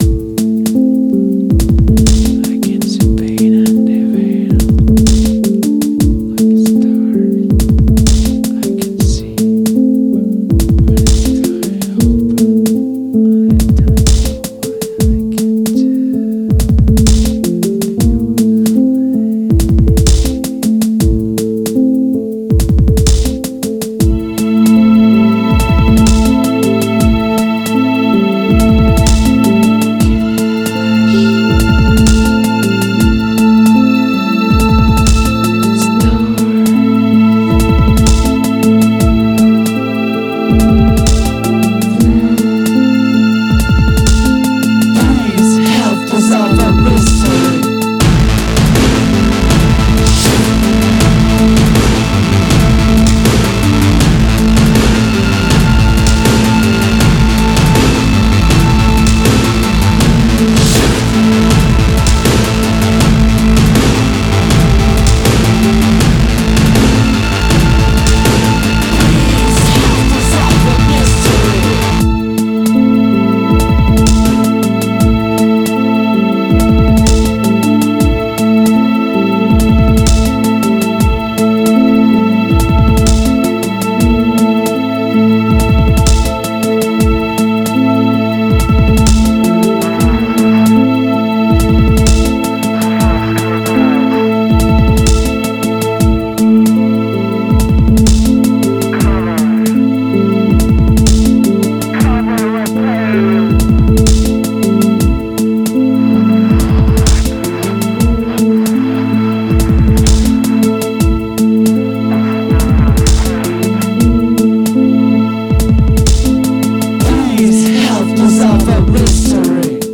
Industrial